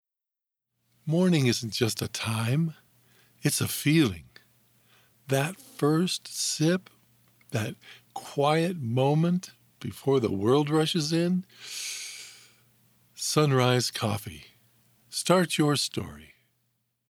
Commercial Narration and Conversational
CommercialDemo_Sunrise Coffee_1.mp3